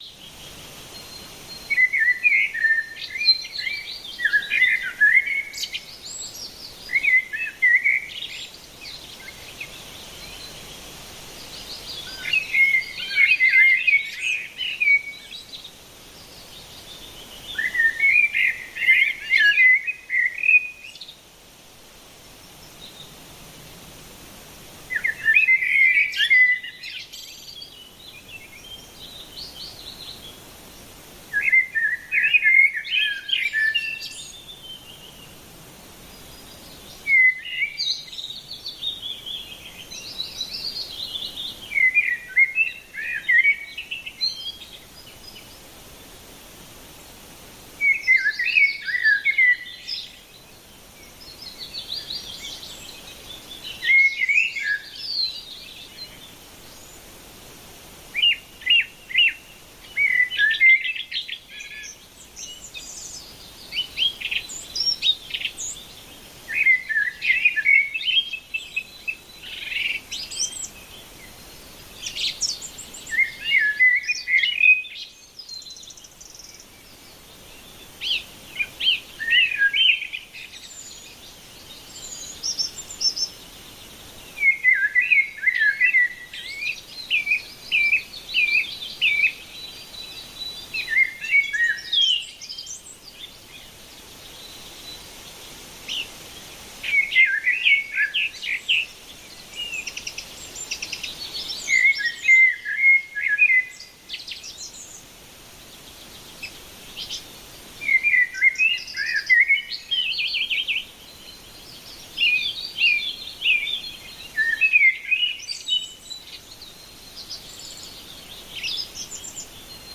MAGISCHES LICHT: Frühlingswald-Blauglöckchen mit Vogel-Gesang
Naturgeräusche